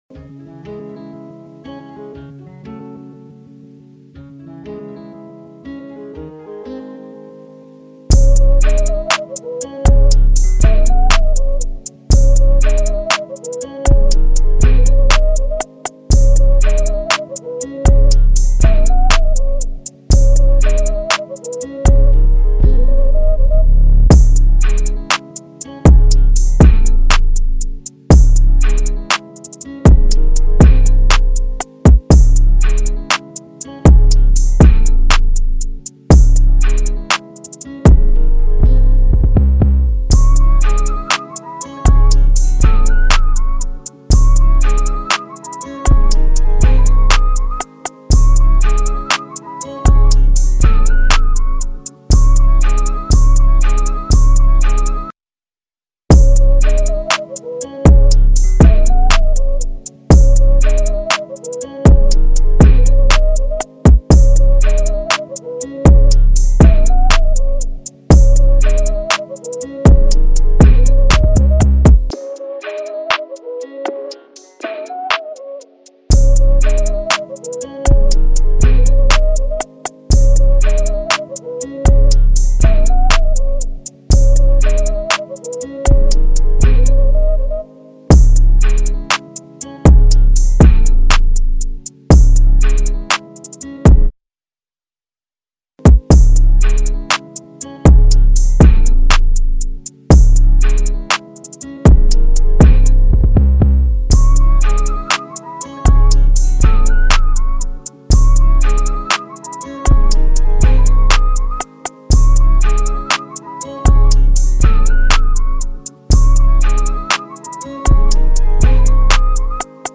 Hip-Hop Instrumental